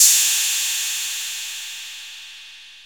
puredata/resources/808_drum_kit/hihats/808-Ride2.wav at c6ec450c5e1d143fec8a820ca6e977c604b781dc
808-Ride2.wav